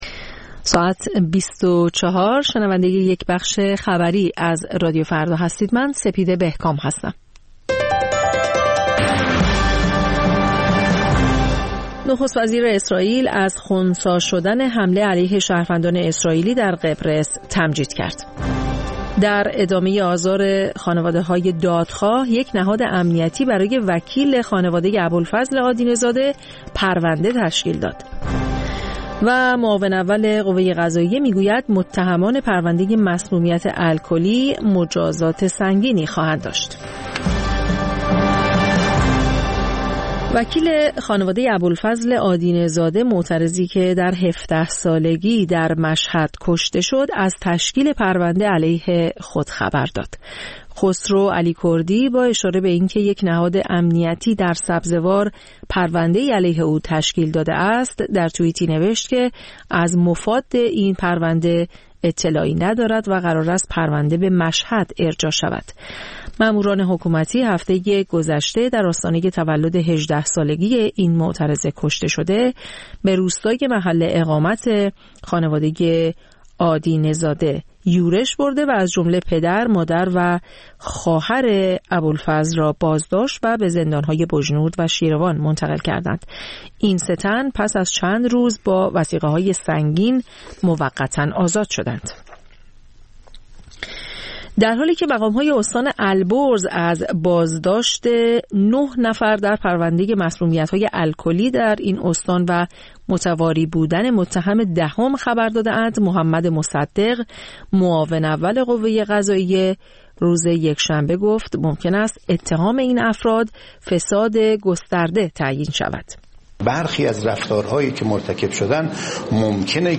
همچون هر روز، مجله نیمه شب رادیو فردا، تازه ترین خبر ها و مهم ترین گزارش ها را به گوش شما می رساند.